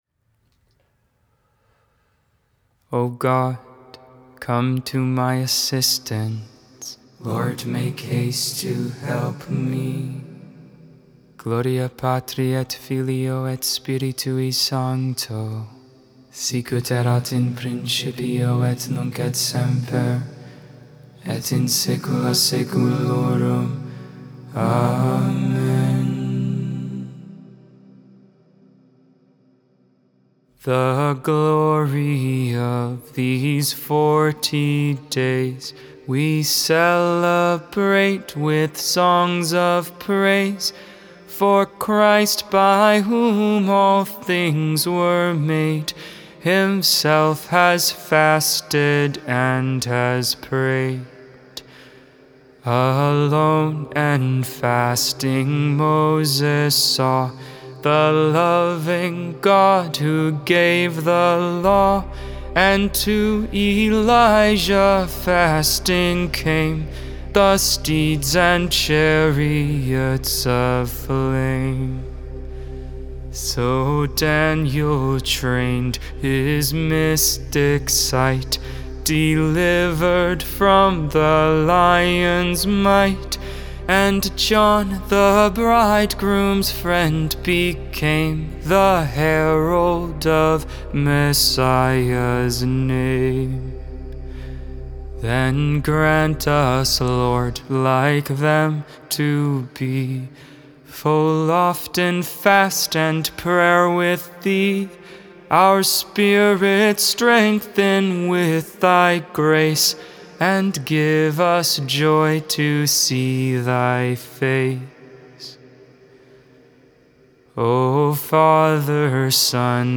2.25.23 Vespers, Saturday Evening Prayer